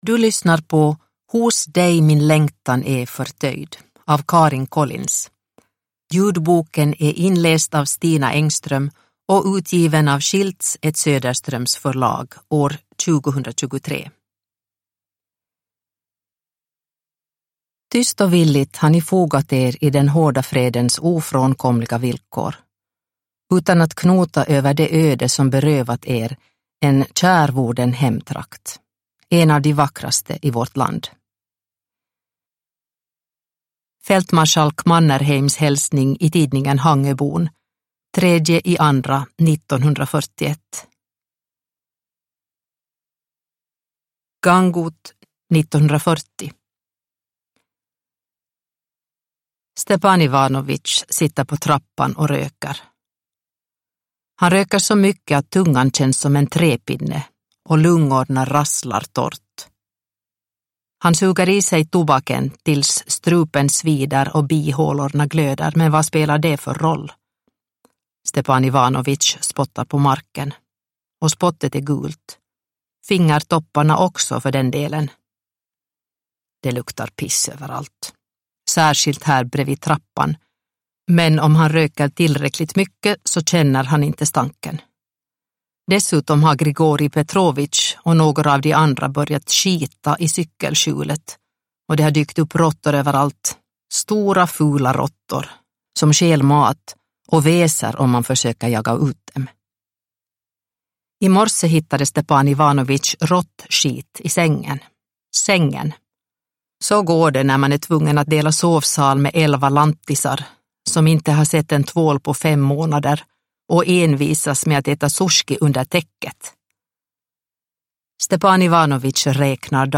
Hos dig min längtan är förtöjd – Ljudbok – Laddas ner